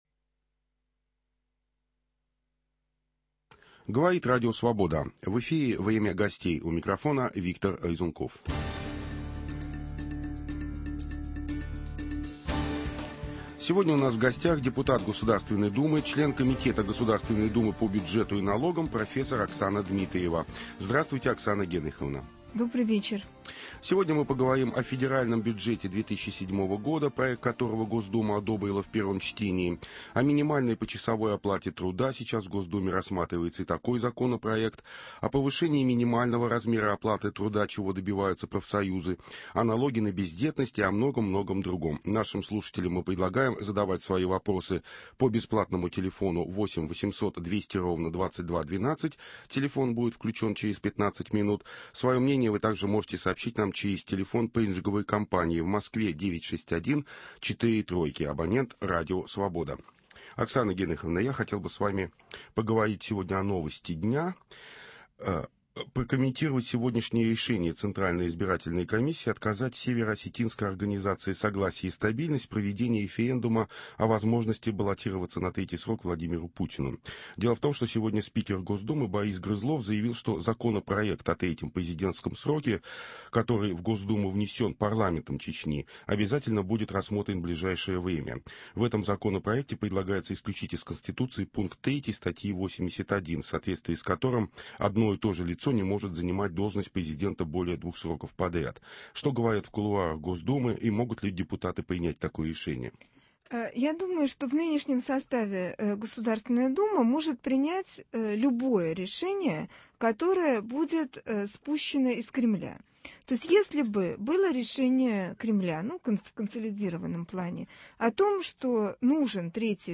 Об этом и многом другом - в беседе с депутатом Государственной думы Оксаной Дмитриевой.